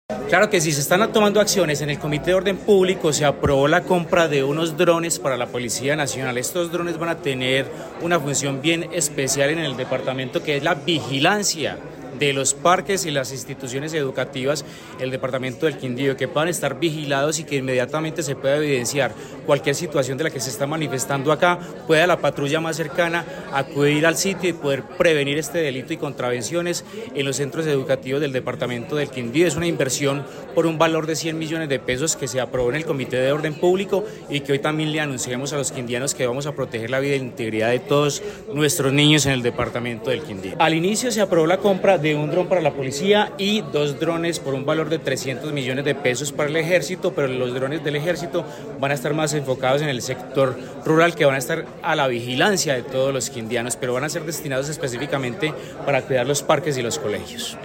Secretario del Interior del Quindío